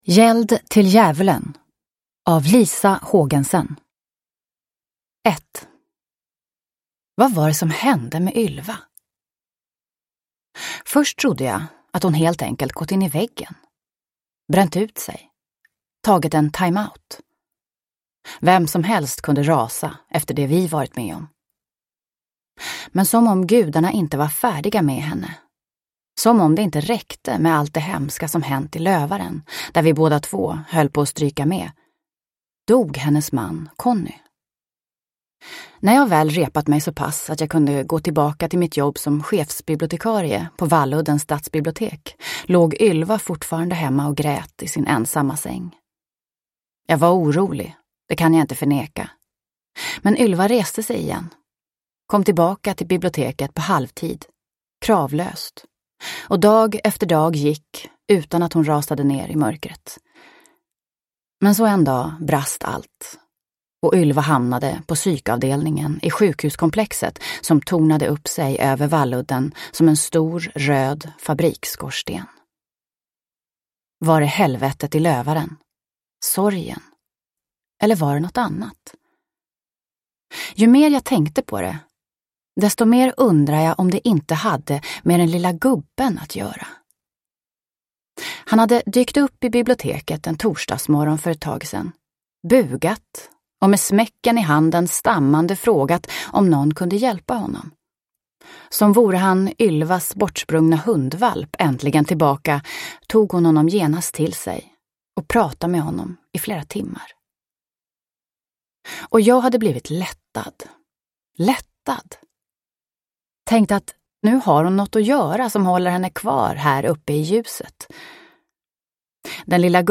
Gäld till djävulen – Ljudbok – Laddas ner